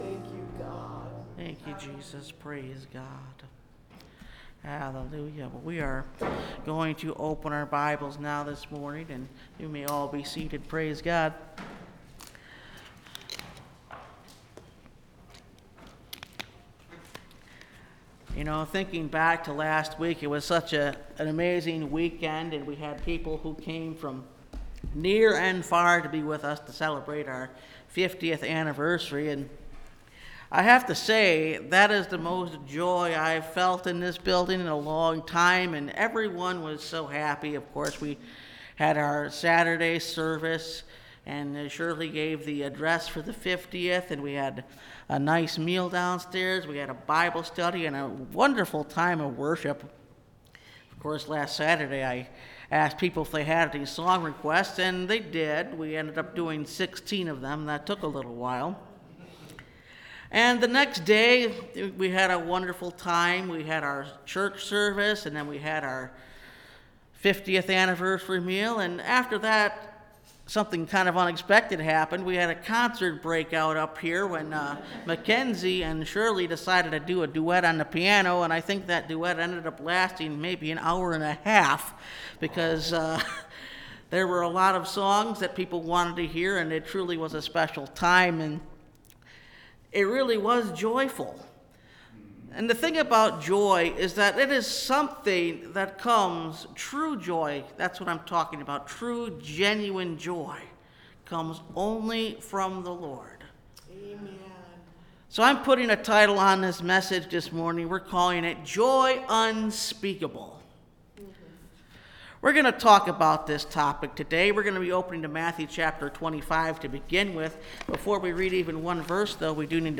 Joy Unspeakable (Message Audio) – Last Trumpet Ministries – Truth Tabernacle – Sermon Library